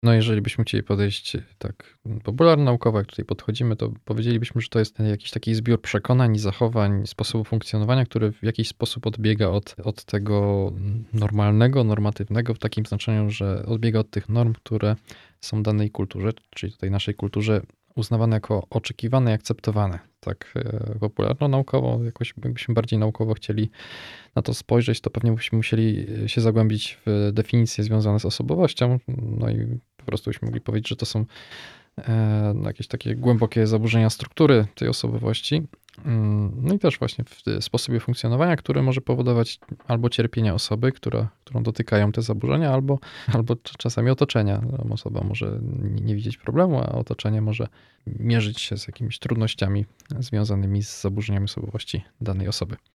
W środowy wieczór, 2 kwietnia o godzinie 20:10 na falach Radia Rodzina odbędzie się kolejna audycja z cyklu „Kwadrans Psychologiczny”.